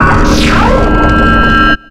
Cri de Spiritomb dans Pokémon X et Y.